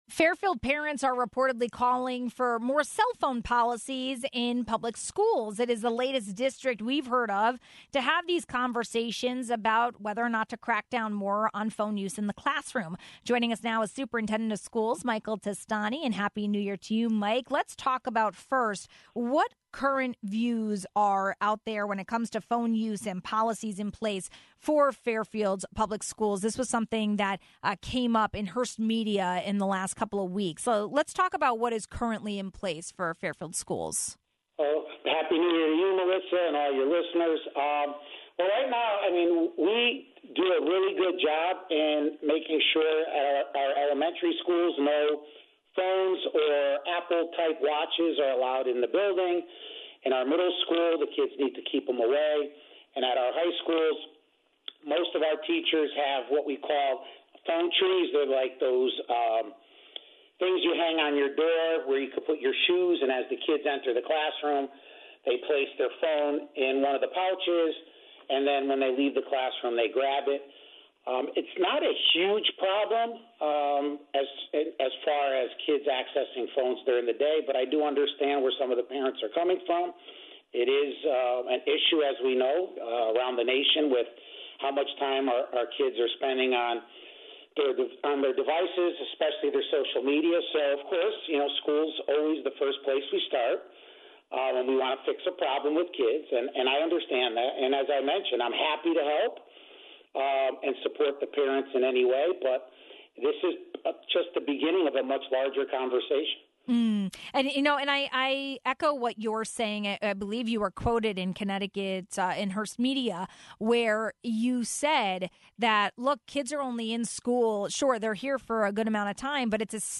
We spoke with Superintendent of Schools, Michael Testani, about the ideas on the table and whether anything will change soon for Fairfield students.